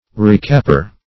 Recapper \Re*cap"per\ (r[-e]*k[a^]p"p[~e]r)
recapper.mp3